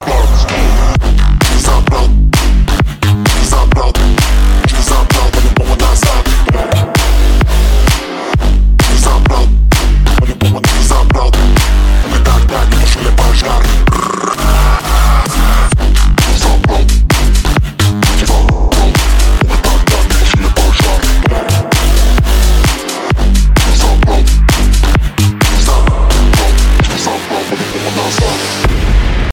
• Качество: 192, Stereo
мощные басы
качающие
взрывные
выстрел